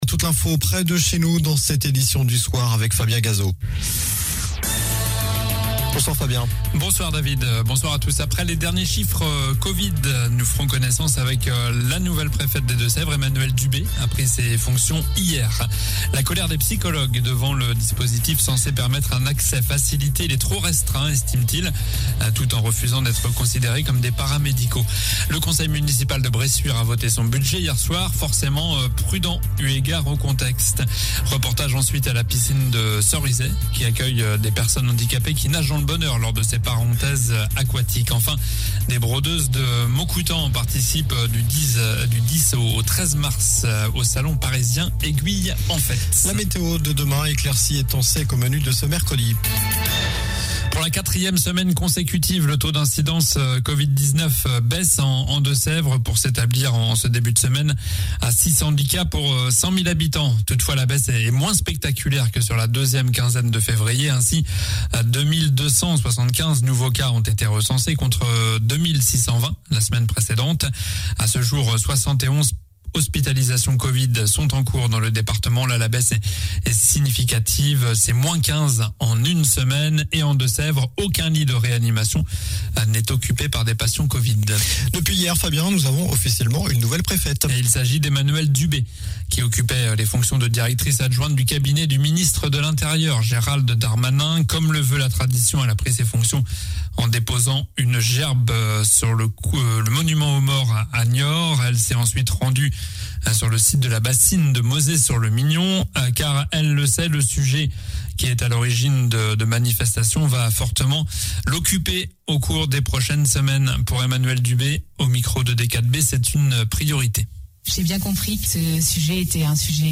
Journal du mardi 08 mars (soir)